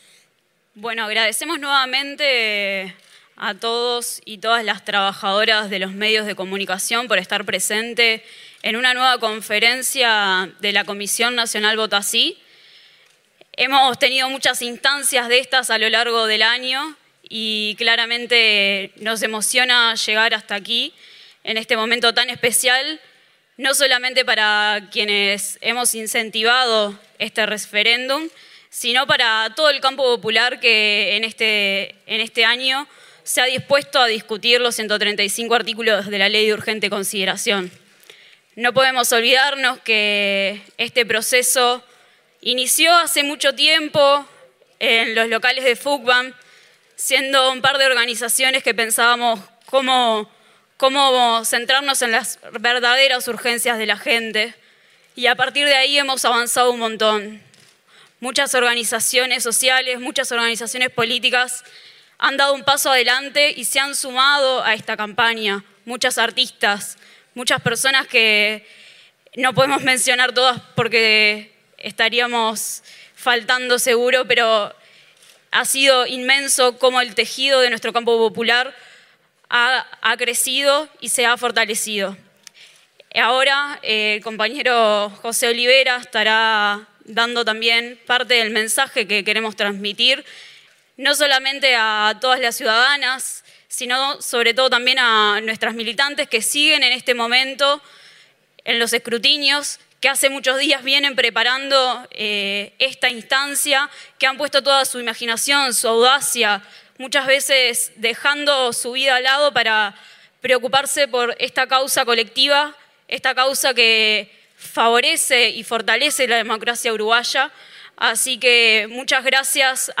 La Comisión Nacional por el Sí brindó, al cierre de la jornada del domingo, una conferencia de prensa con un mensaje final de evaluación de la campaña y proyección a futuro, pese a que aún no se conocía el resultado definitivo.